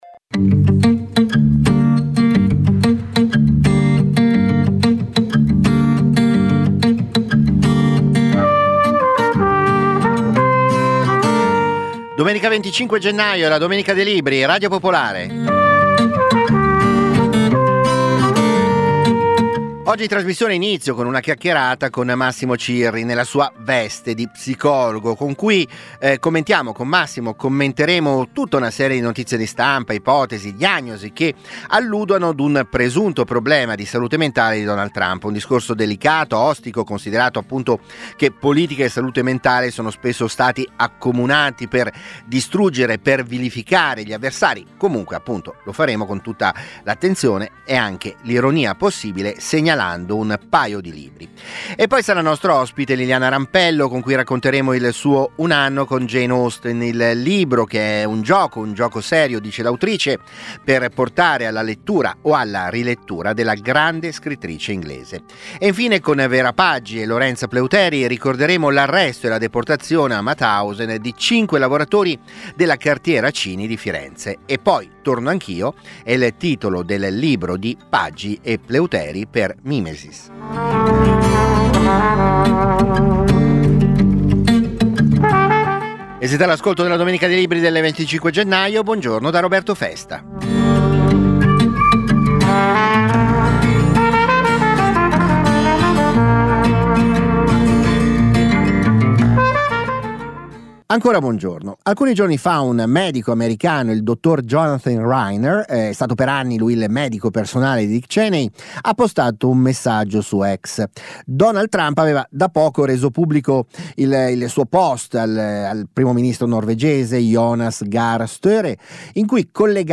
La domenica dei libri è la trasmissione di libri e cultura di Radio Popolare. Ogni settimana, interviste agli autori, approfondimenti, le novità del dibattito culturale, soprattutto la passione della lettura e delle idee.